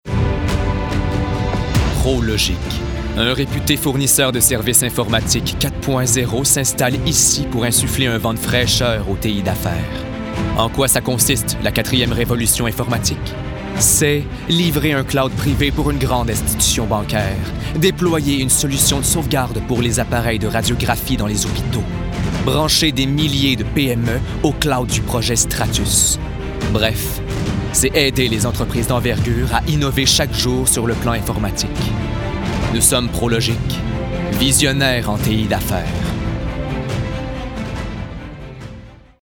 Publicité 1